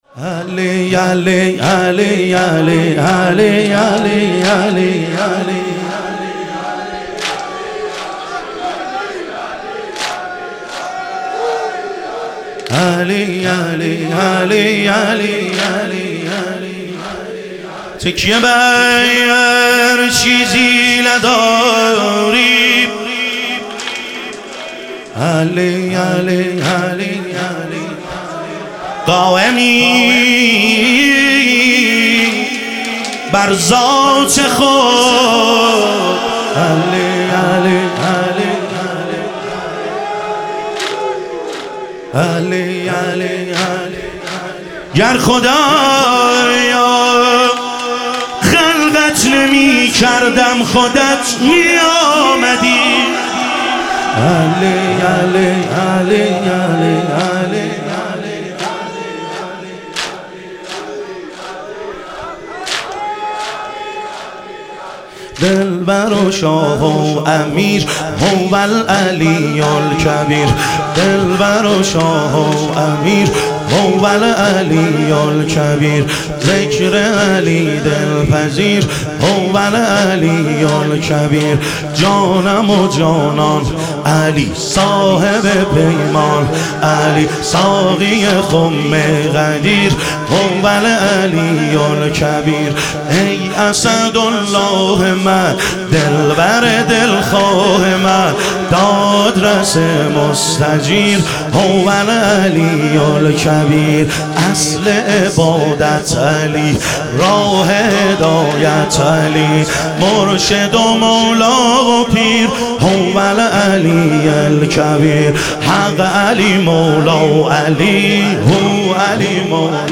شهادت امام صادق علیه السلام - واحد